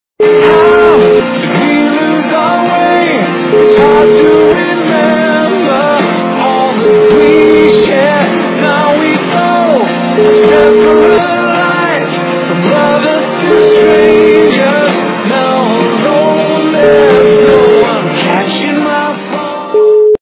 - западная эстрада
качество понижено и присутствуют гудки